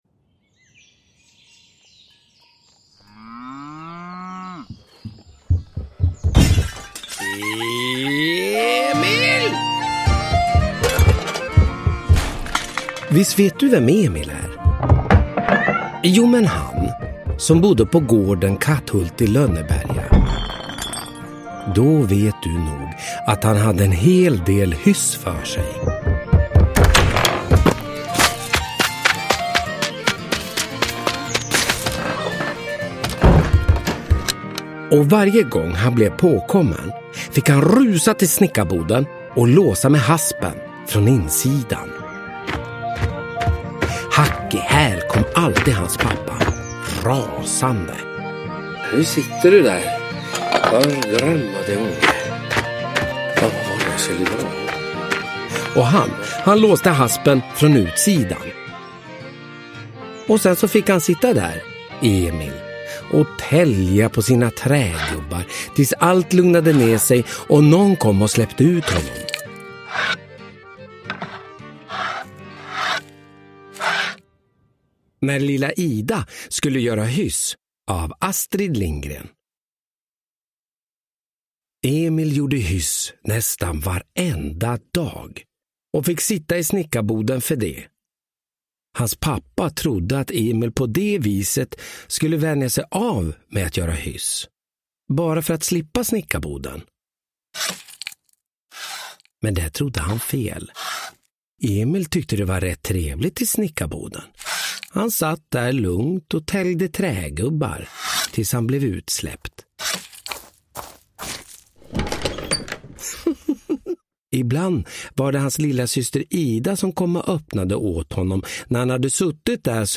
Ny inläsning av Emil i Lönneberga med stämningsfull ljudläggning!
Uppläsare: Olof Wretling